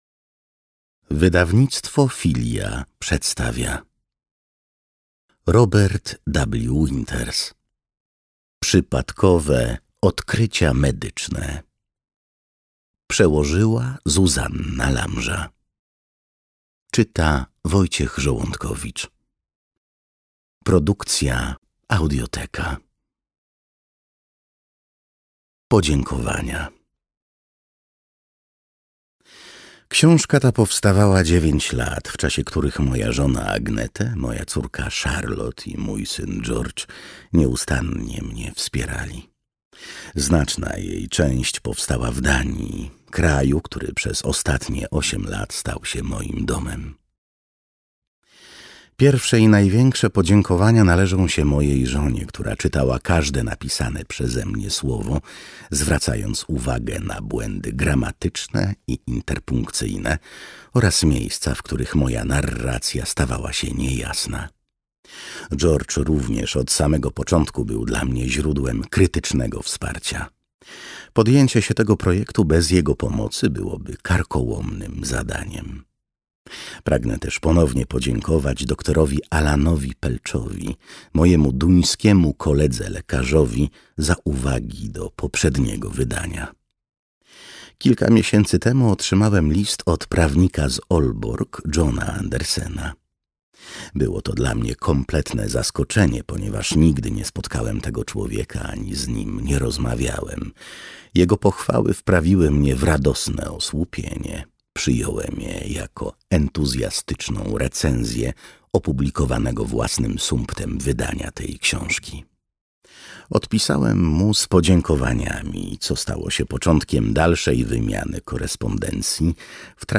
Przypadkowe odkrycia medyczne - Robert W. Winters - audiobook